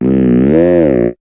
Cri de Chelours dans Pokémon HOME.